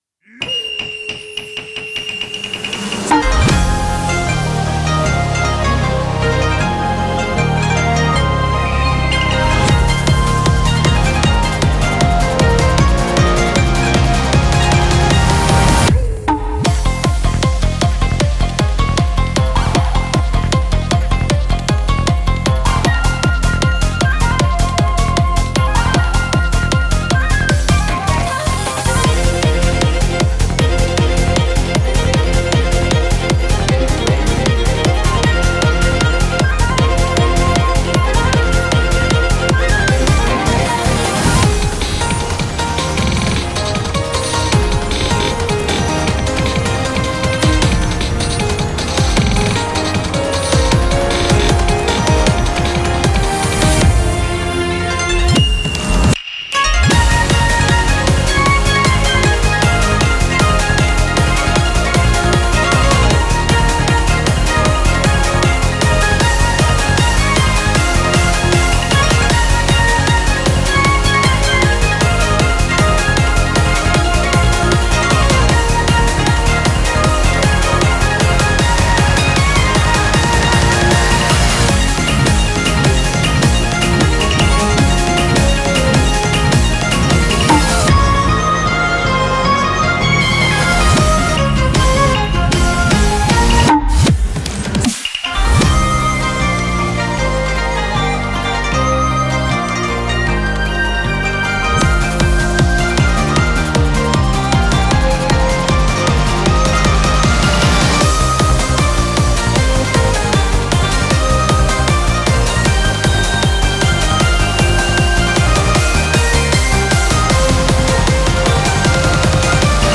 BPM155